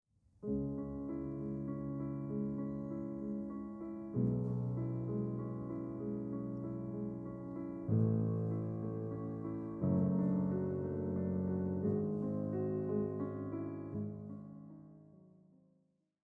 The triplets in the right hand has an almost hypnotizing effect because they are repeated over and over again.